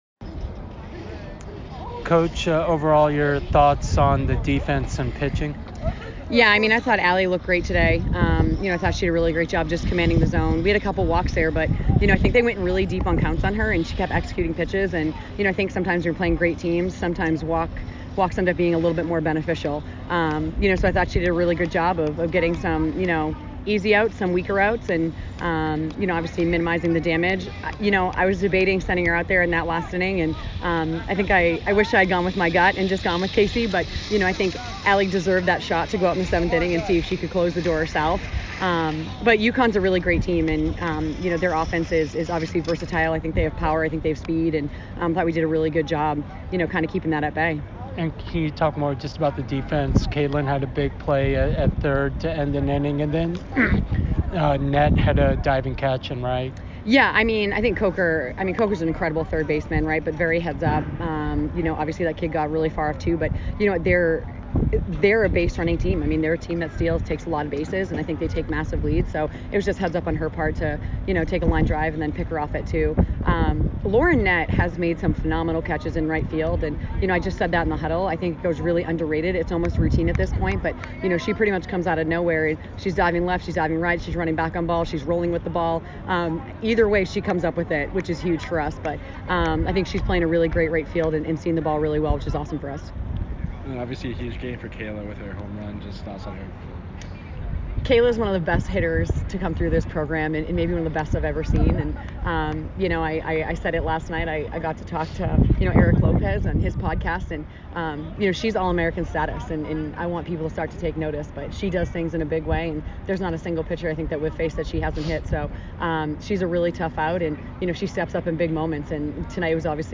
Softball / UConn Postgame Interview (3-29-23) - Boston University Athletics